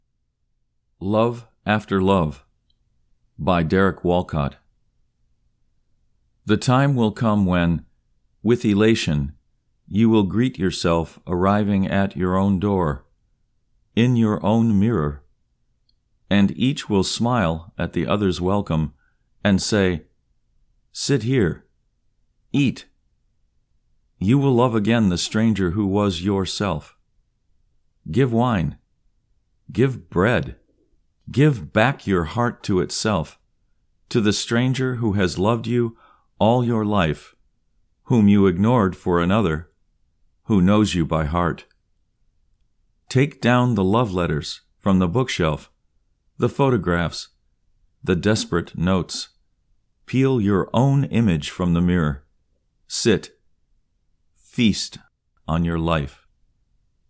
Love After Love © by Derek Walcott (Recitation)